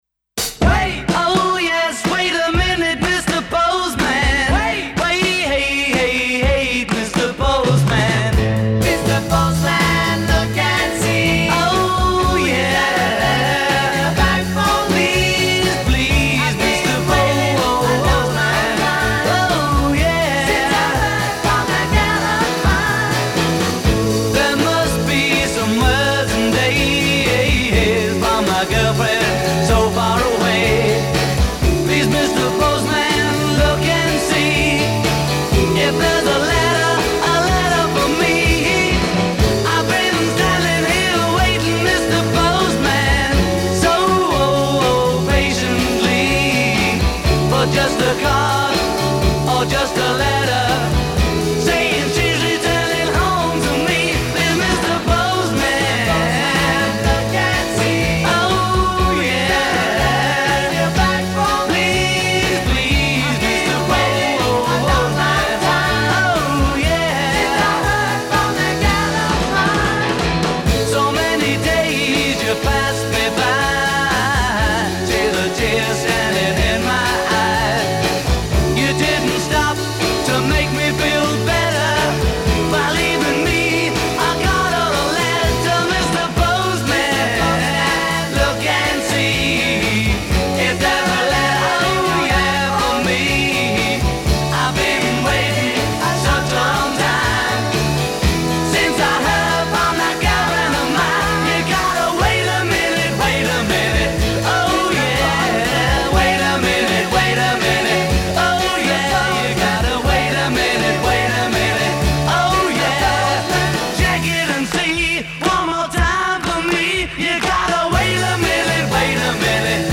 it was recorded in a single day along with a few others